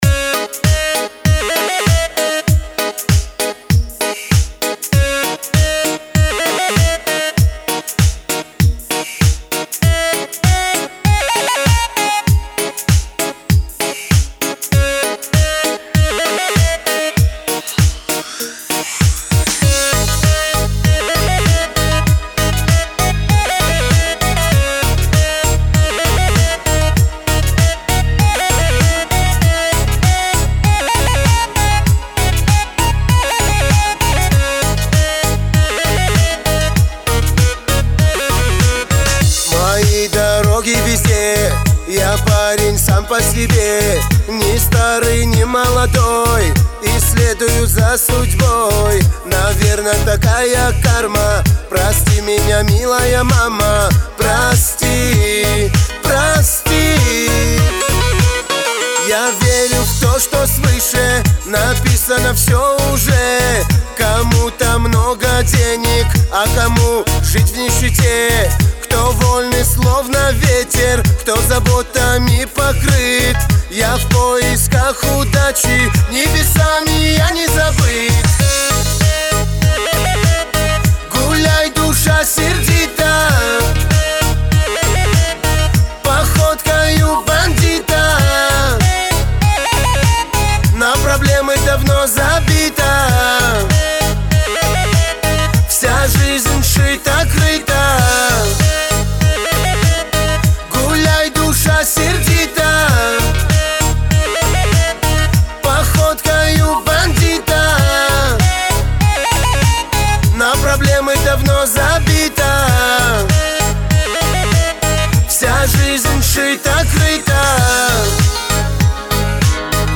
Категория: Шансон 2016